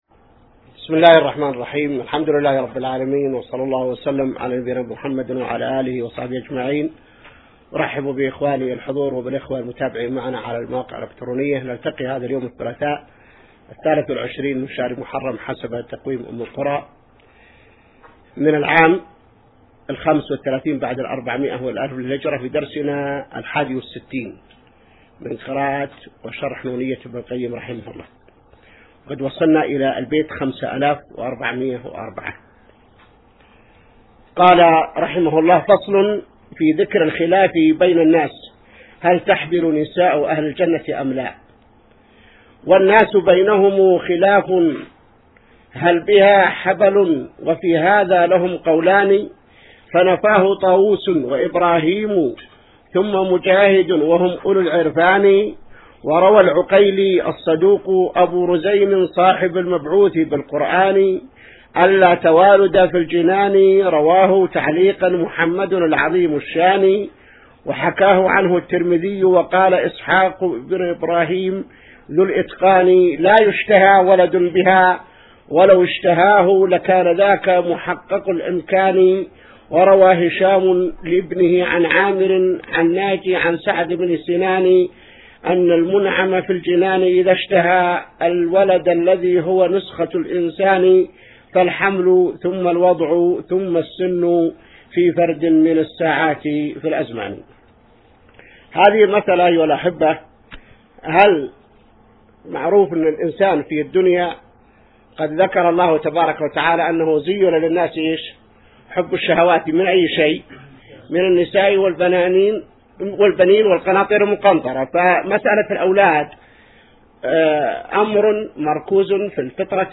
الدرس 61 من شرح نونية ابن القيم | موقع المسلم